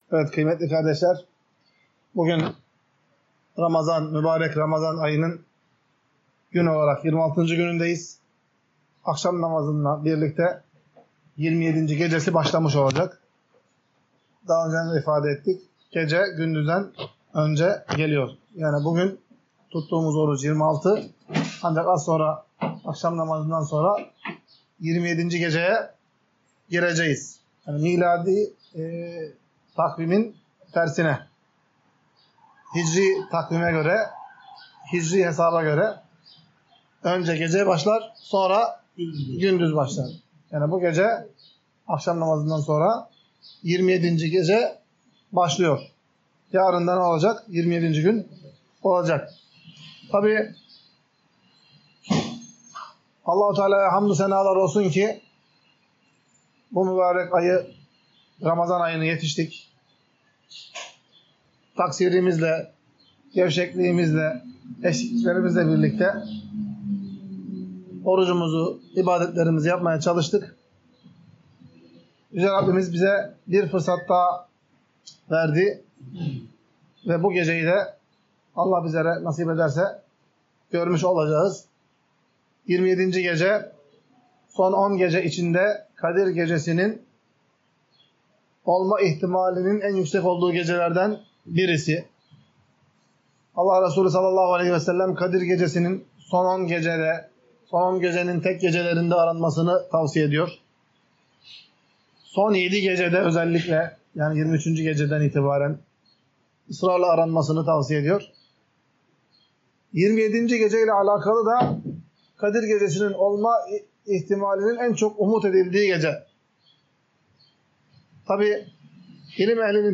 Ders - 35.